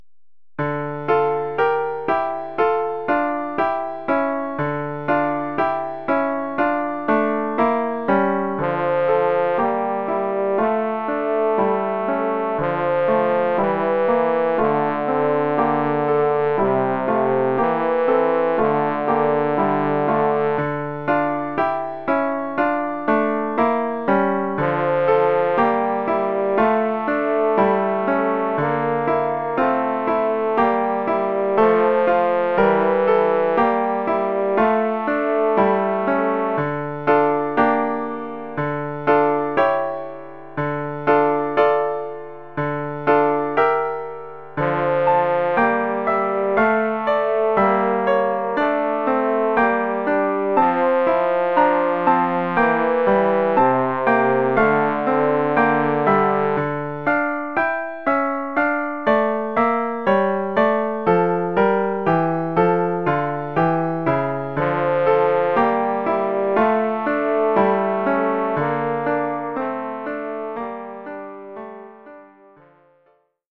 Formule instrumentale : Trombone et piano
Oeuvre pour trombone et piano.